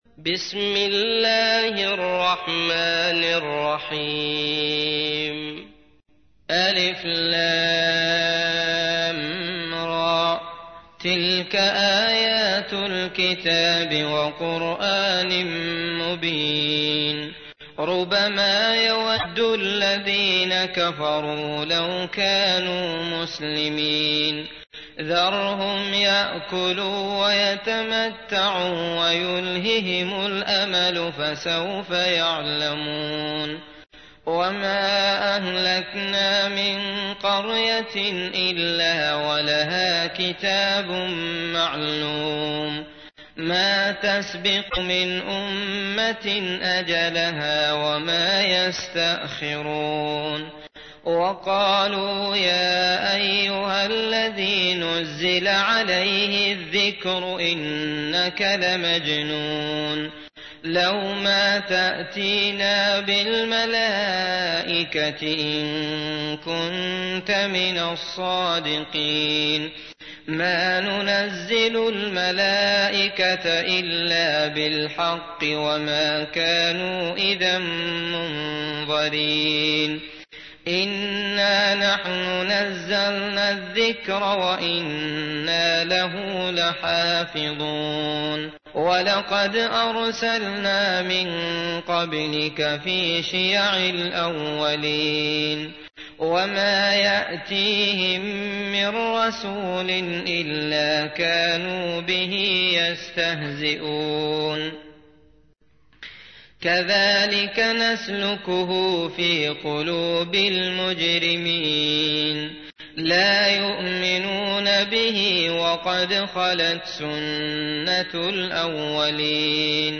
تحميل : 15. سورة الحجر / القارئ عبد الله المطرود / القرآن الكريم / موقع يا حسين